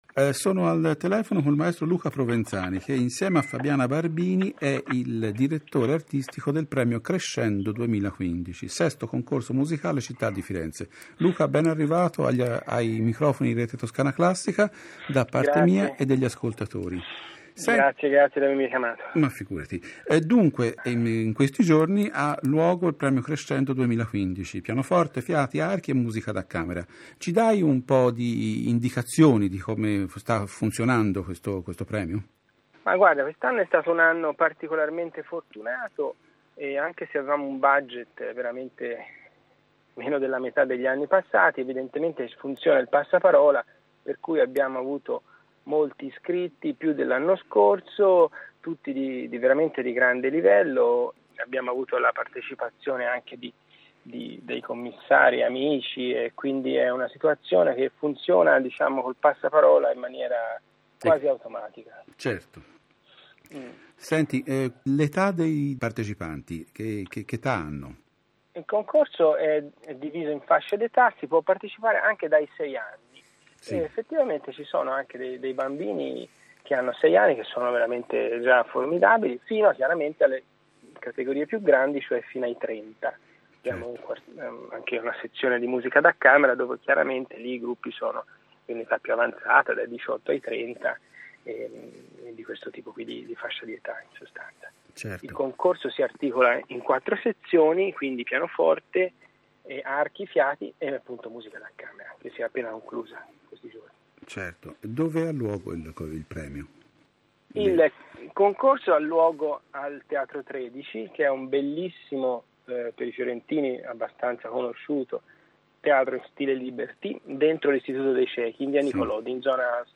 Ascolta l’intervista sul Premio Crescendo